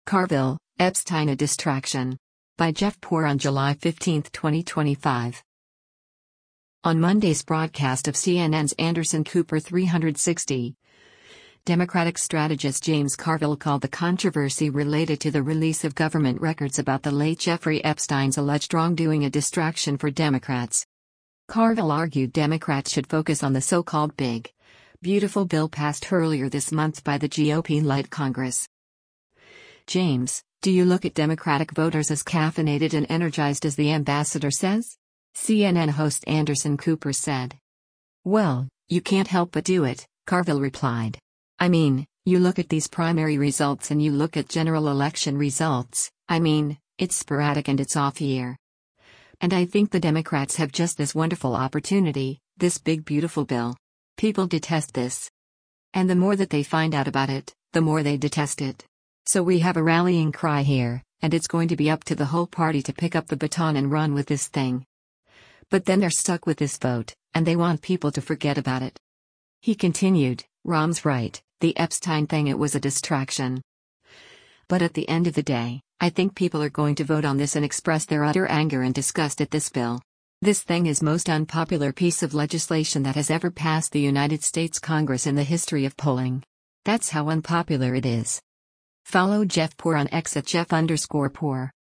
On Monday’s broadcast of CNN’s “Anderson Cooper 360,” Democratic strategist James Carville called the controversy related to the release of government records about the late Jeffrey Epstein’s alleged wrongdoing a “distraction” for Democrats.